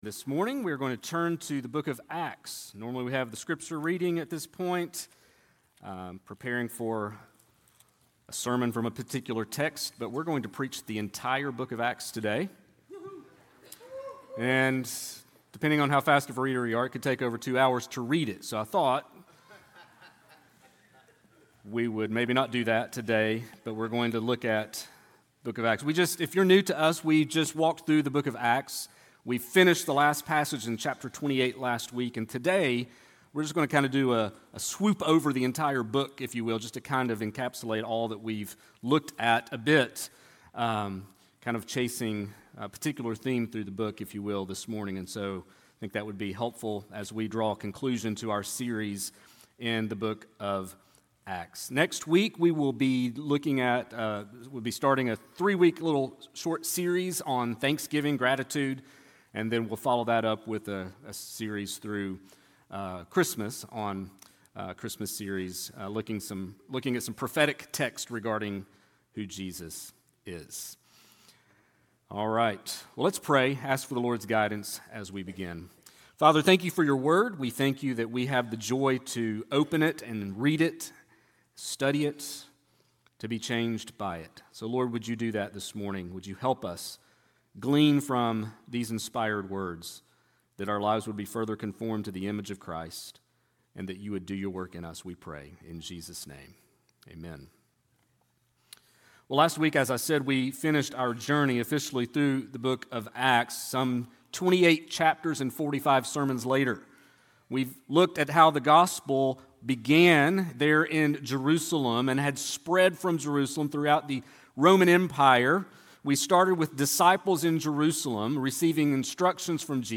sermon11.2.25.mp3